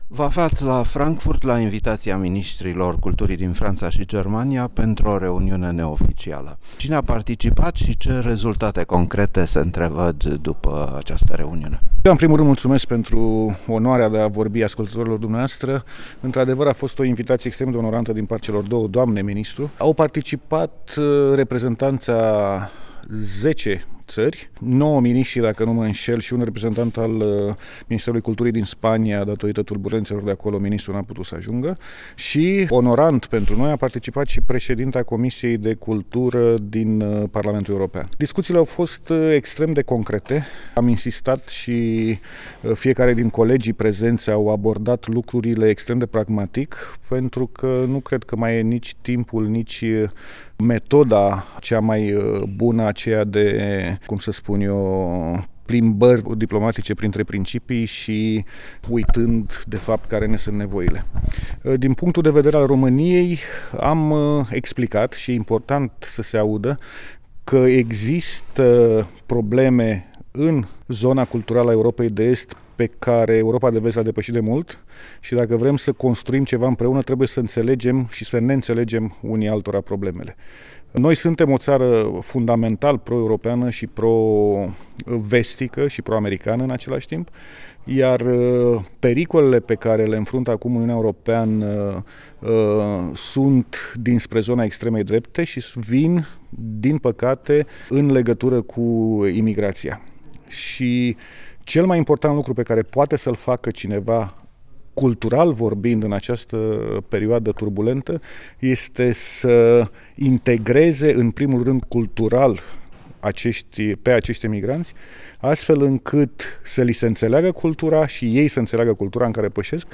Un interviu cu ministrul român al culturii la Tîrgul Internațional de Carte de la Frankfurt.
De vorbă la Tîrgul Internațional de Carte de la Frankfurt cu Lucian Romașcanu, ministrul român al culturii